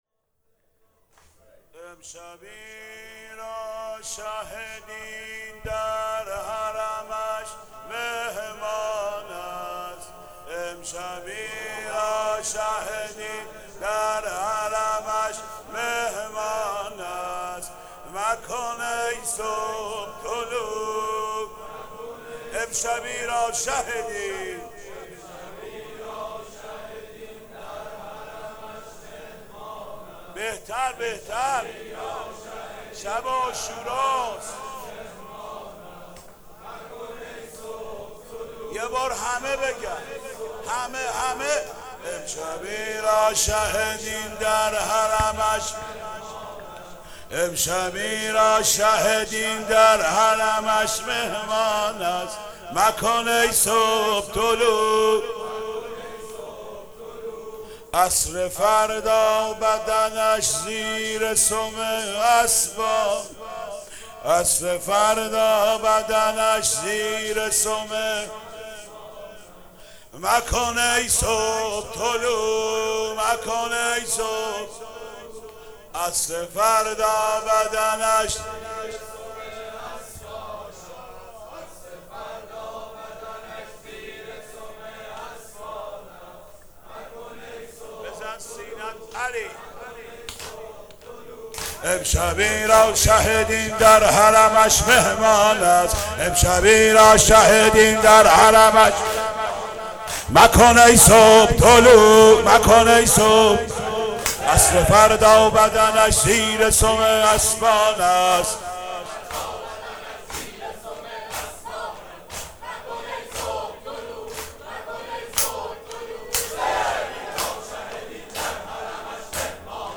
سخنرانی
روضه